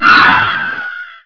pain.wav